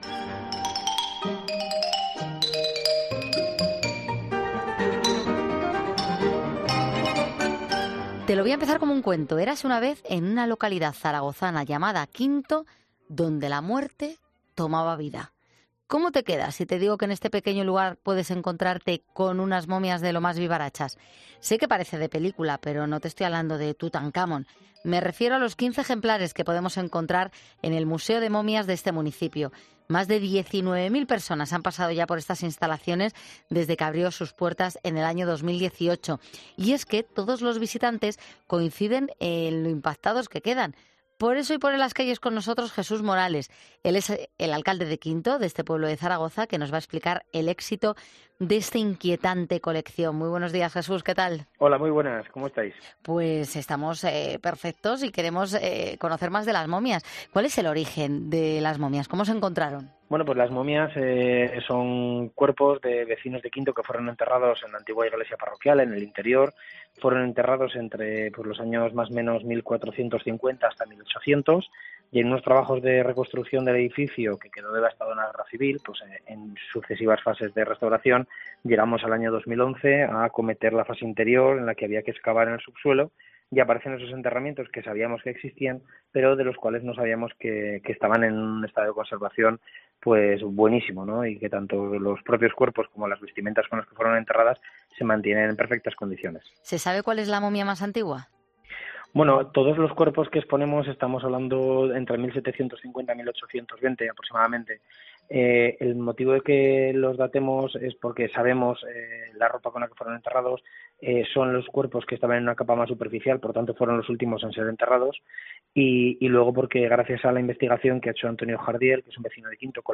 AUDIO: Su alcalde Jesús Morales ha pasado por los micrófonos de 'Poniendo las Calles' para contarnos las claves y las curiosidades de esta colección tan...